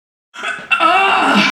Play, download and share kjj moan original sound button!!!!